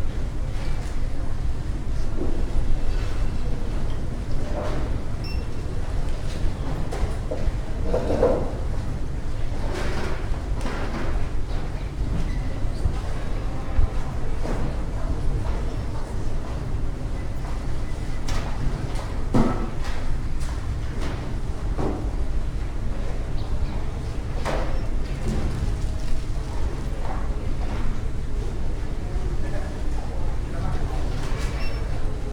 library.ogg